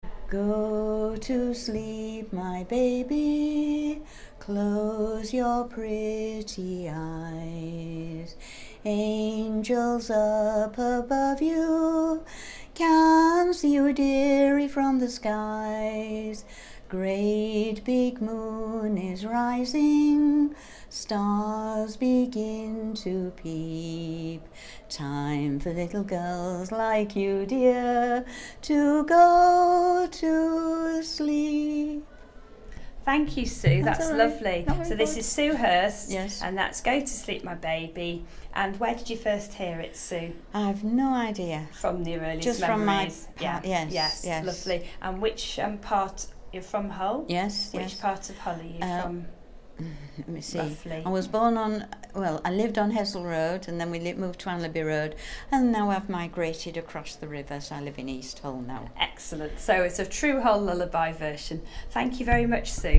Lullaby_recording Public